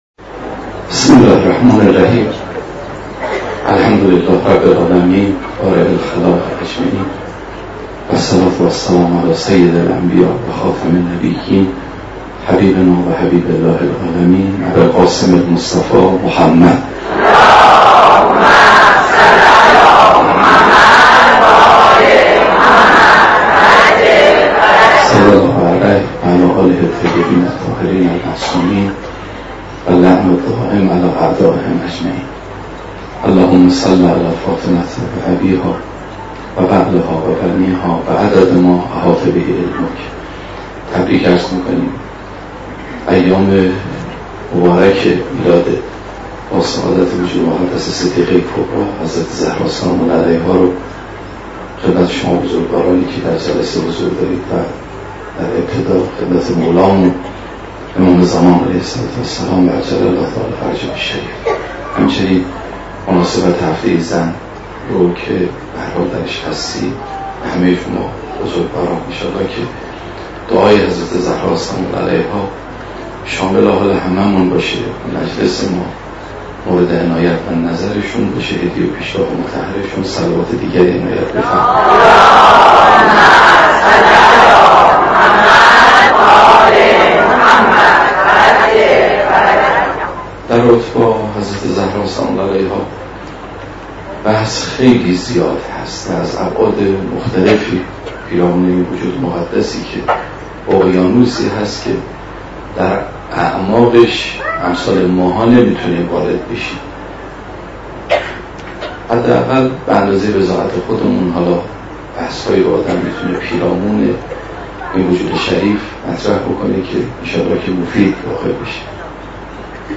جشن میلاد حضرت فاطمه"س"